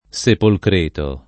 [ S epolkr % to ]